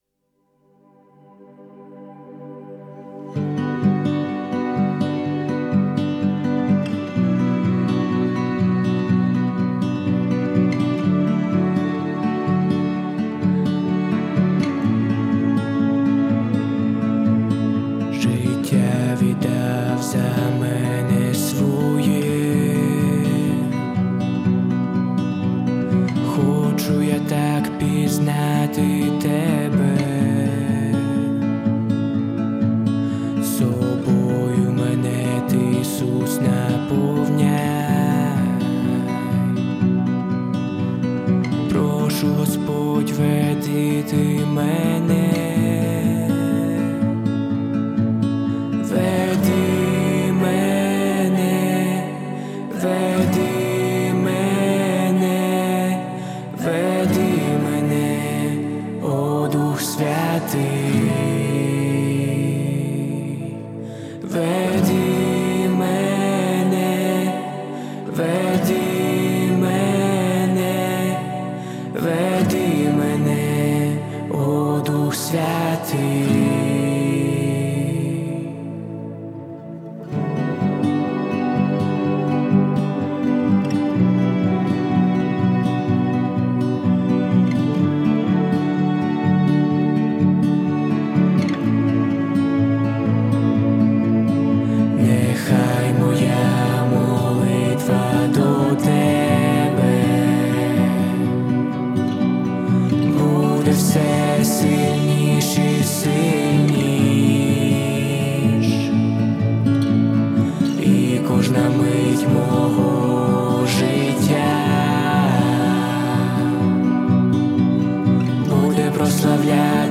252 просмотра 187 прослушиваний 34 скачивания BPM: 125